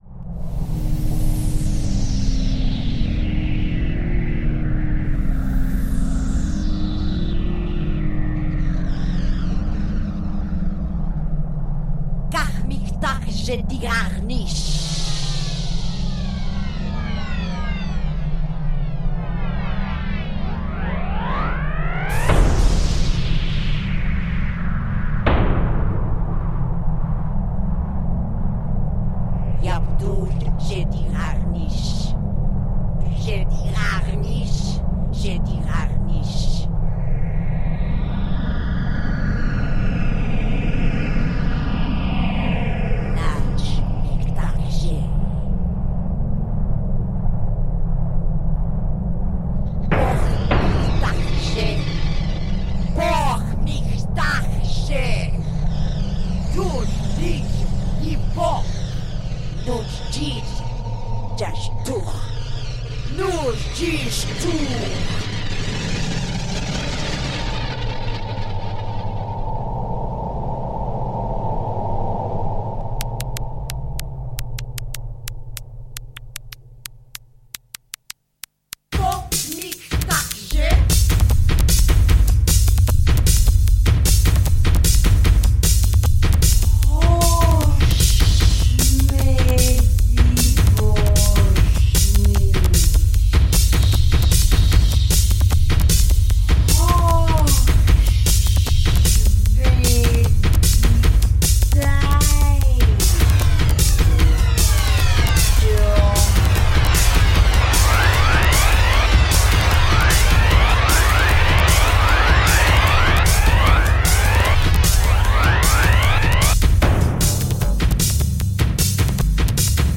It is orchestral music produced with electronic devices
The vocals are Klingon and Chinese."